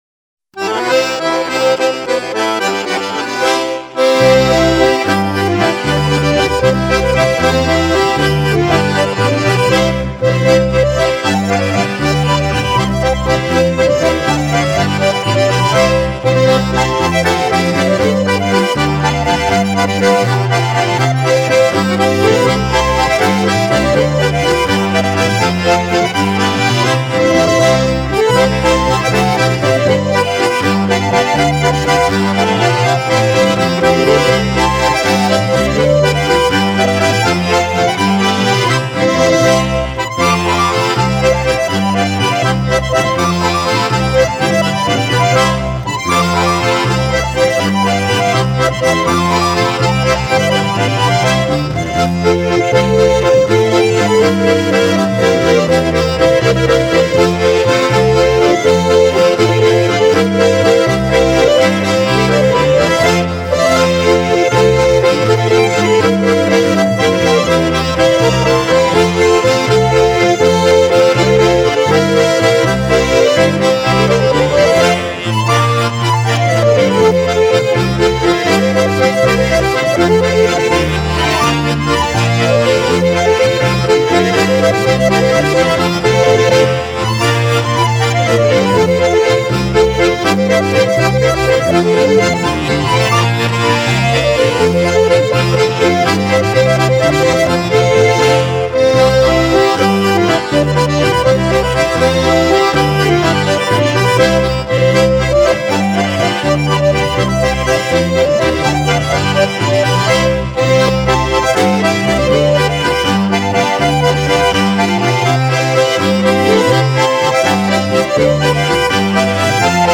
Ländler